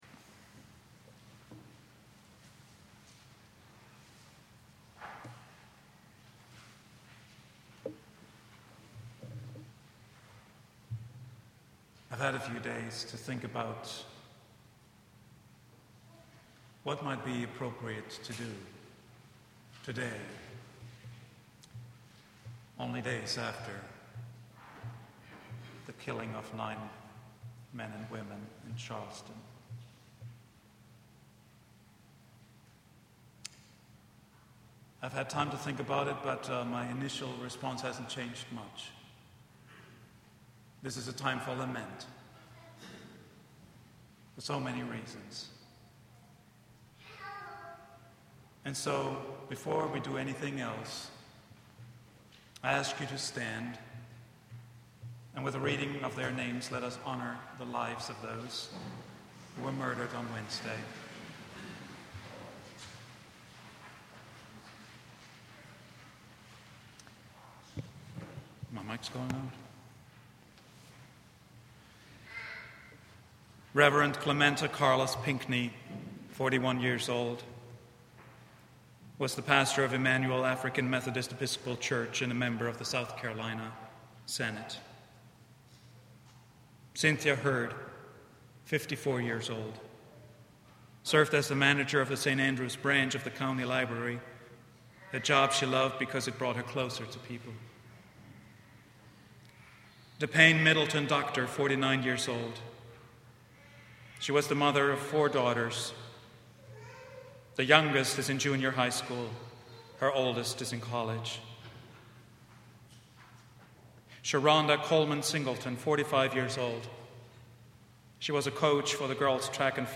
sermon
preached at Vine Street Christian Church